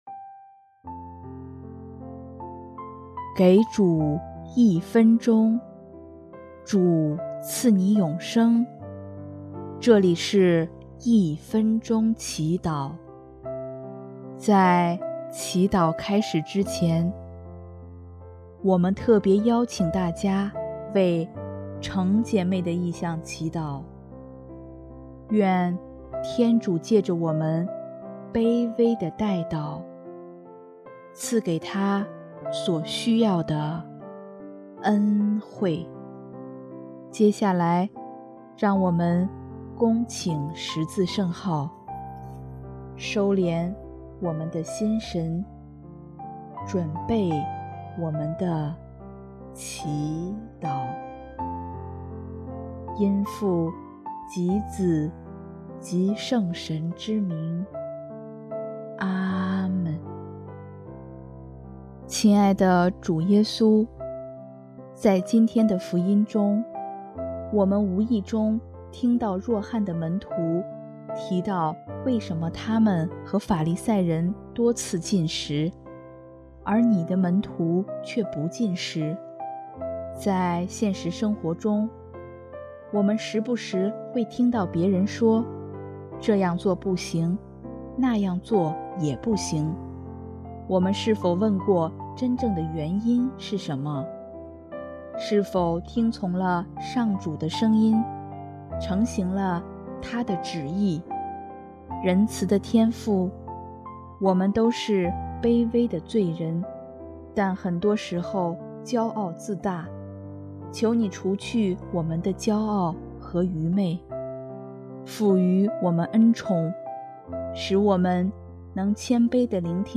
【一分钟祈祷】|3月7日 以谦卑聆听到声音